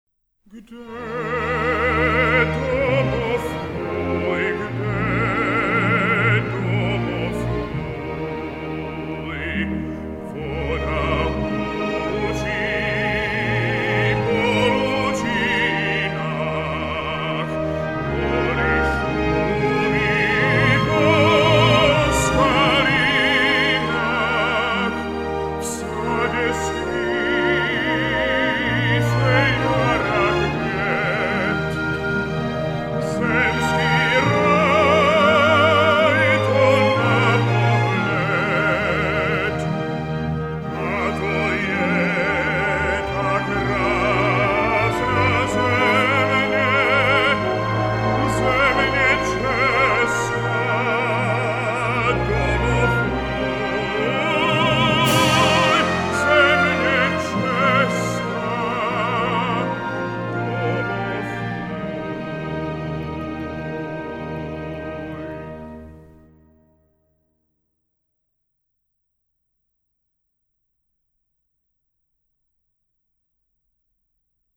Zvukový záznam nové nahrávky české státní hymny - muľský sólový zpěv v podání Adama Plachetky